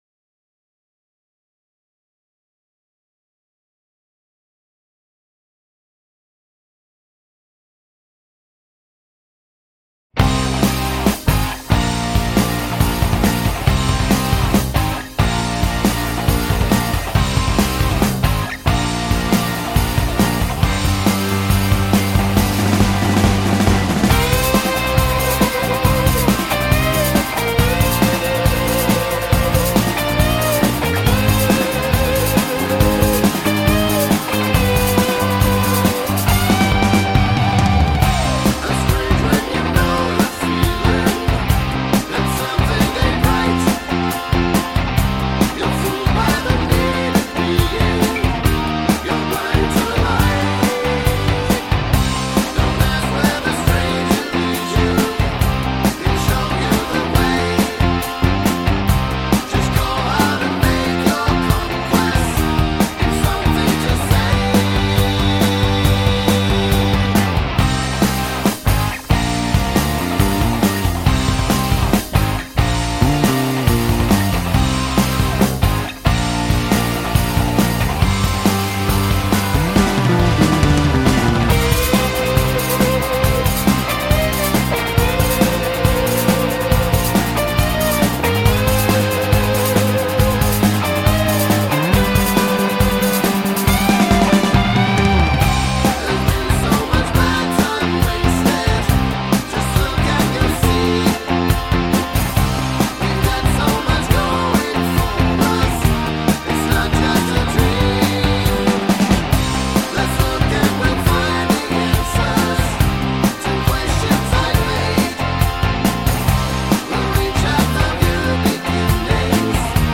Karaoke Version